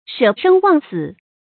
shě shēng wàng sǐ
舍生忘死发音
成语正音 舍，不能读作“shè”。